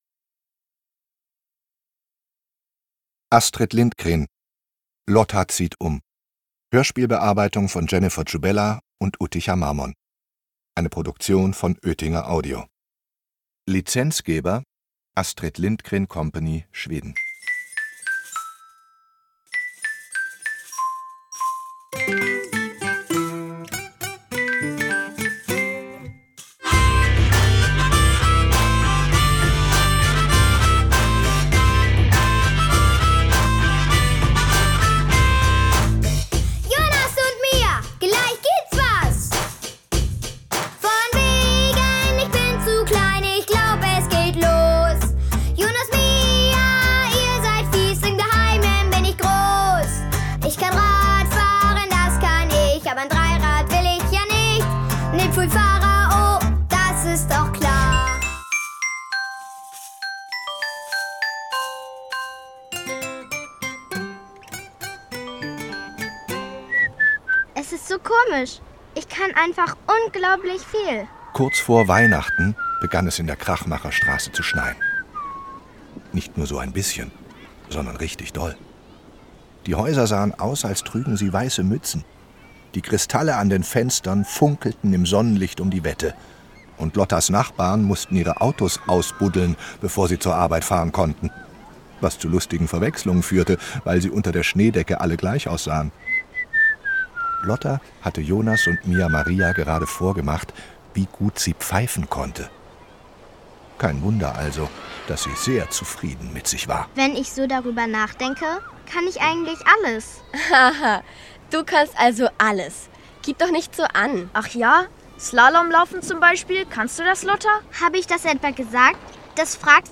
Hörbuch: Lotta zieht um.
Lotta zieht um. Das Hörspiel